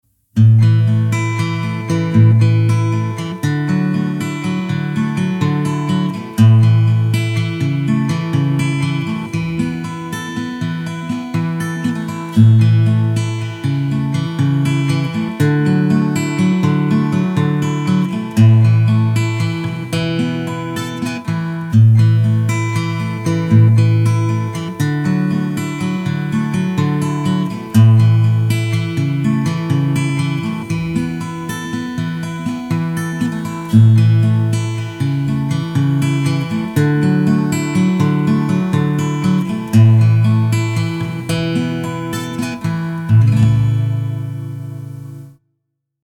Gitarren Aufnahme in Ordnung?
Ich habe als Mikrofon das "Studio Projects B1" verwendet und als Gitarre meine "Axman". Die Gitarre war mal sehr billig, ich glaub so um die 50 Euro hat sie mal neu gekostet.